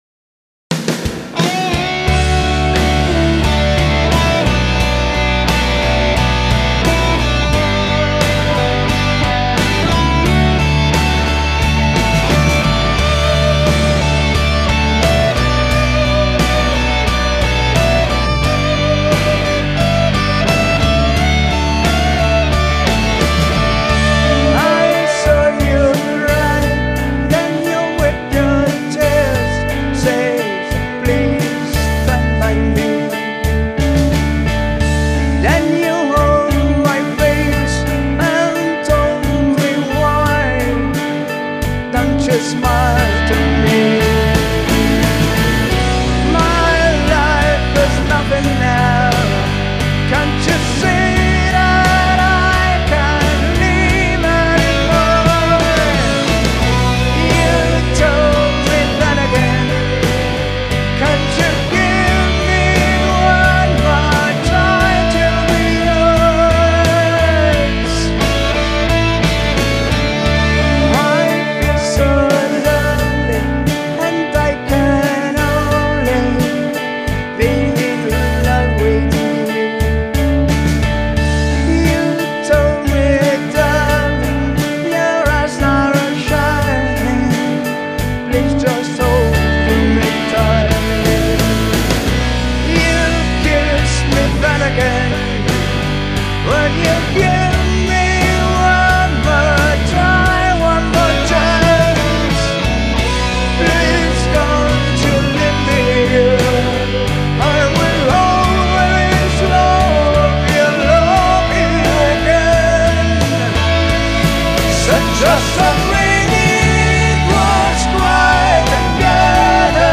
A ROCK BALLADE
Prog rock
Pop